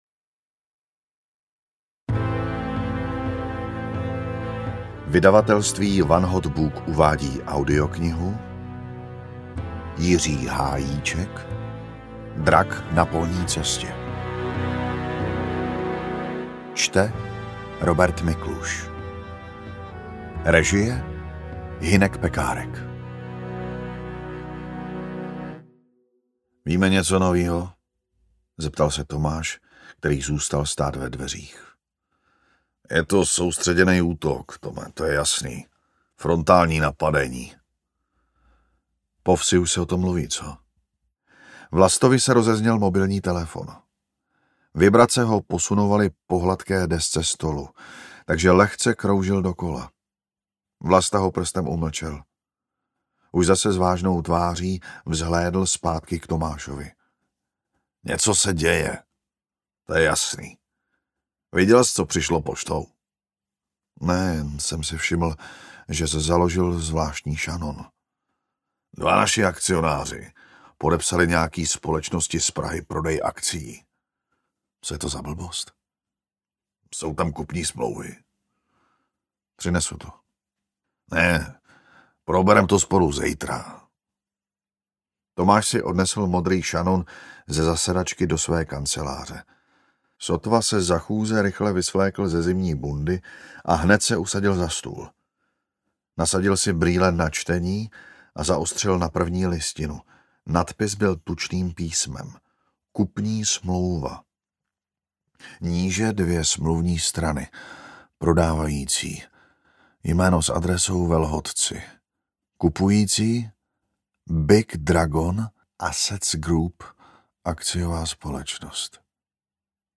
Drak na polní cestě audiokniha
Ukázka z knihy
• InterpretRobert Mikluš